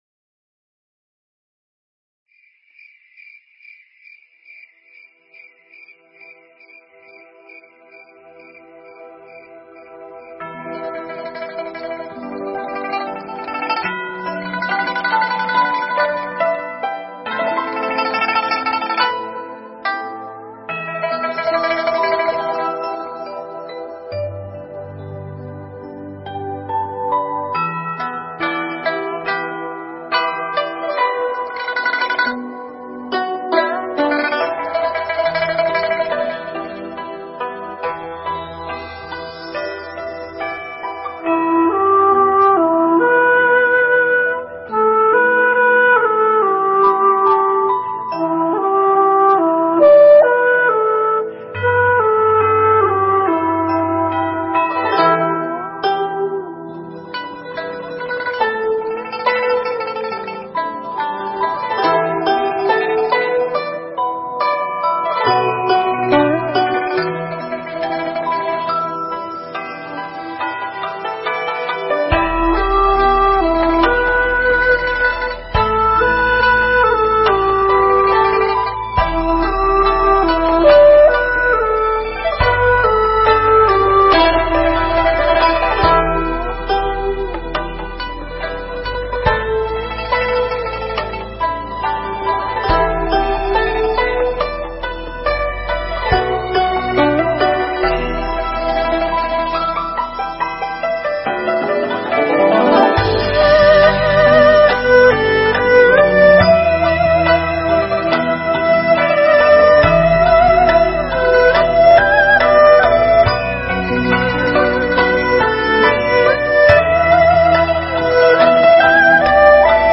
Thuyết Giảng
thuyết giảng tại Tu Viện Trúc Lâm, Canada